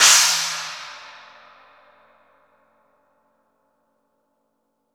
Index of /90_sSampleCDs/AKAI S6000 CD-ROM - Volume 3/Crash_Cymbal2/CHINA&SPLASH
S18CHINA.WAV